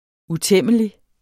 Udtale [ uˈtεmˀəli ]